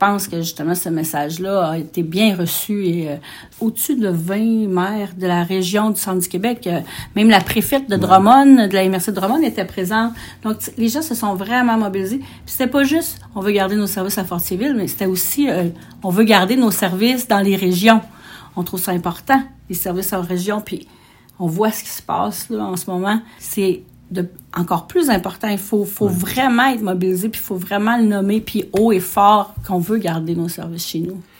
C’est du moins ce qu’a avancé en entrevue la mairesse de Fortierville, Julie Pressé.